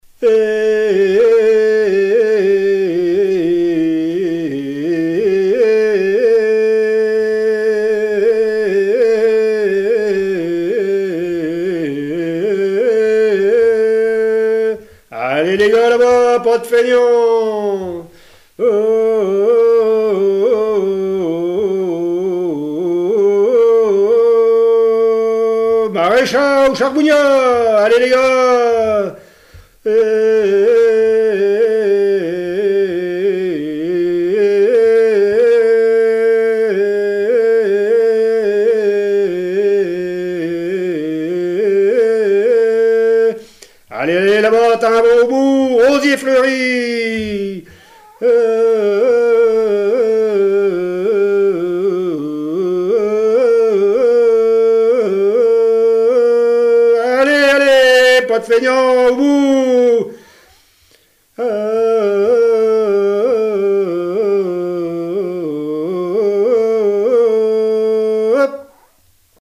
Dariolage
Appels de labour, tiaulements, dariolage, teurlodage, pibolage
couplets vocalisés
Bocage vendéen